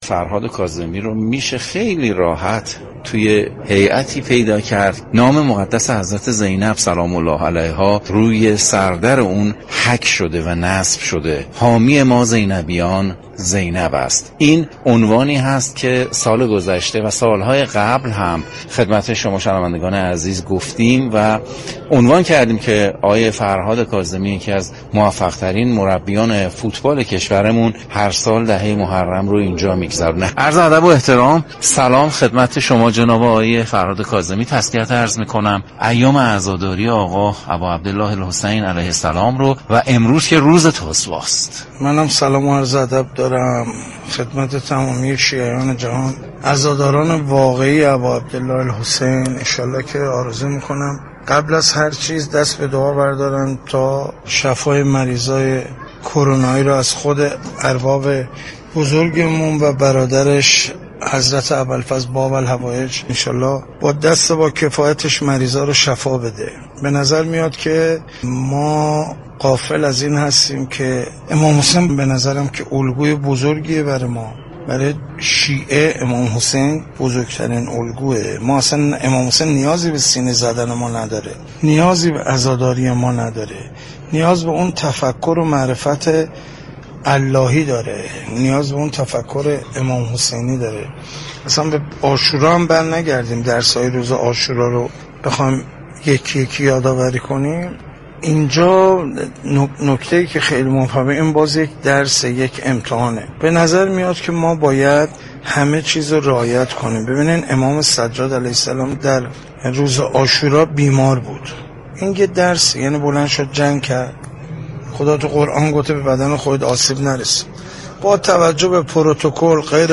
در واقع ما باید بدانیم كه چكار باید كنیم كه عزادار واقعی حضرت امام حسین (ع) باشیم. شما می توانید از طریق فایل صوتی پیوست شنونده این گفتگو با رادیو ورزش باشید.